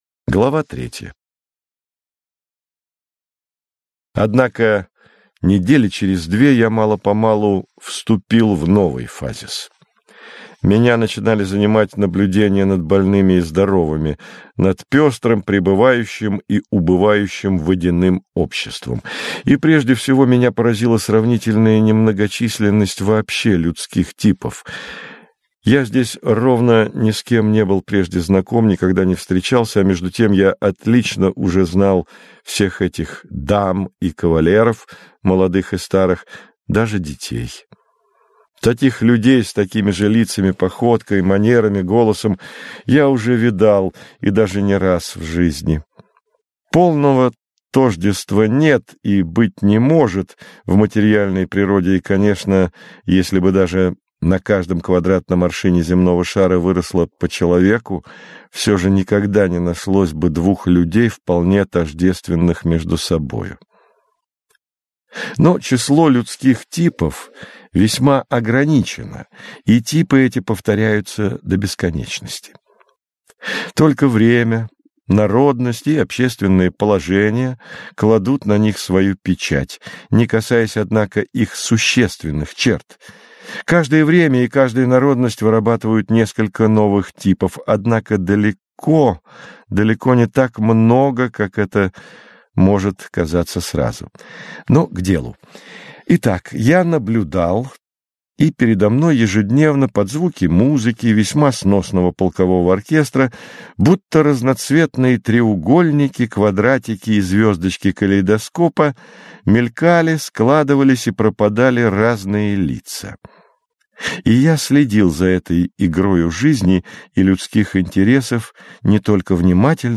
Аудиокнига Гений | Библиотека аудиокниг